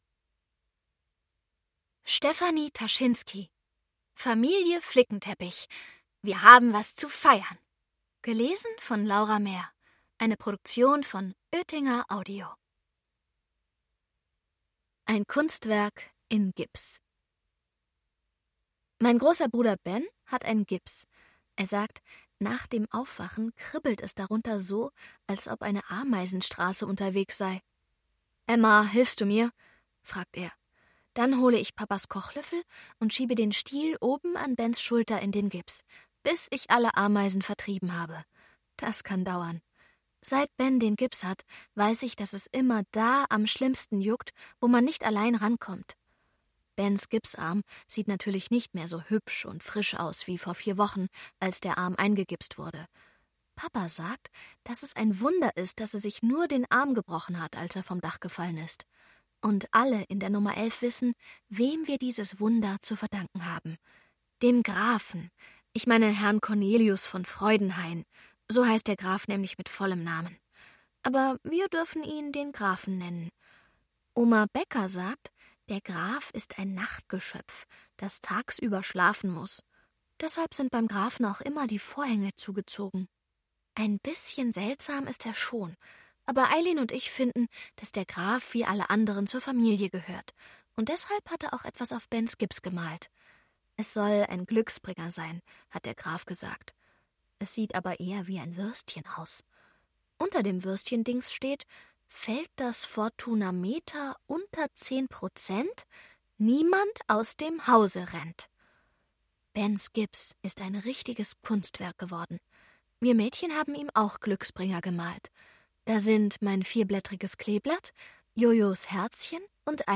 Hörbuch: Familie Flickenteppich 2.